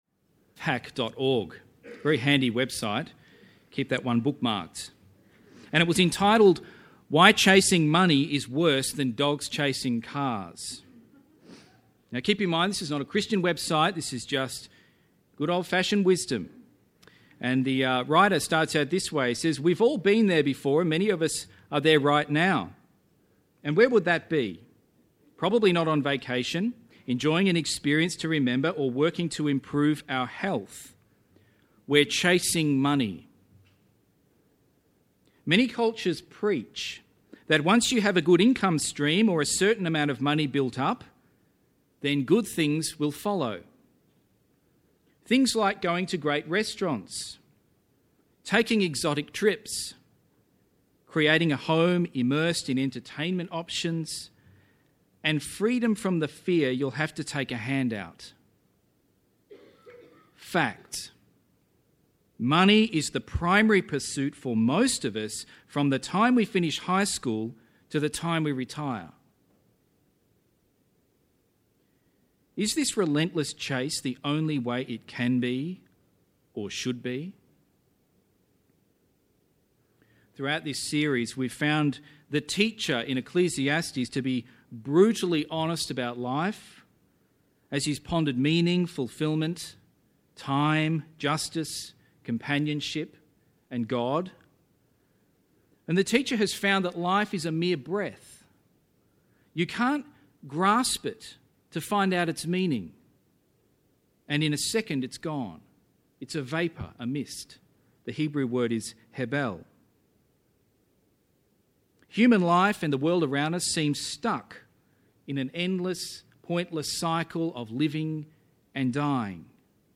This is the final sermon in our Ecclesiastes series.